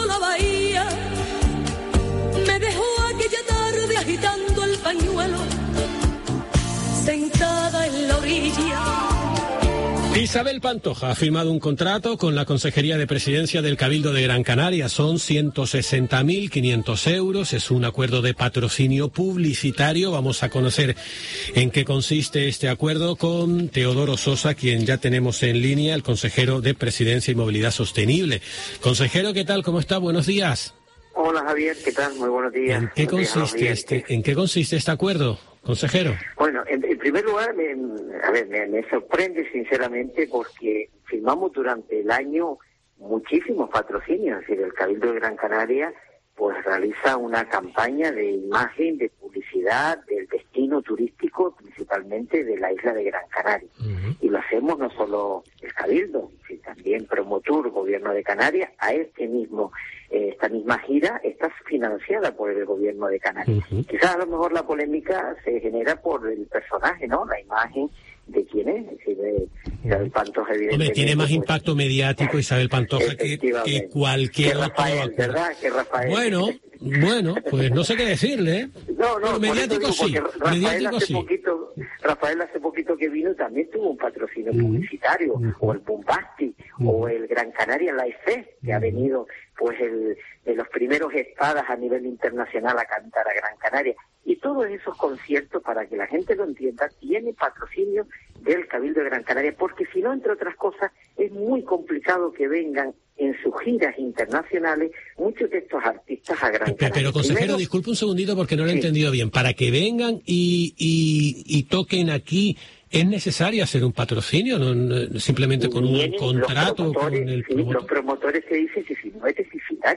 Teodoro Sosa, consejero de Presidencia y Movilidad Sostenible del Cabildo de Gran Canaria